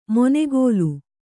♪ monegōlu